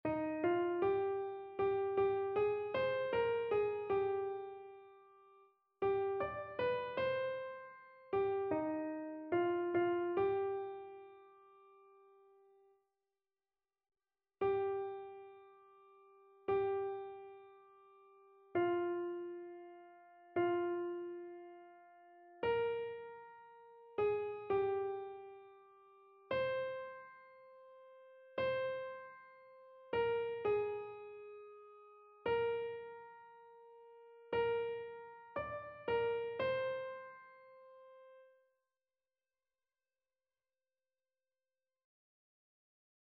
Chœur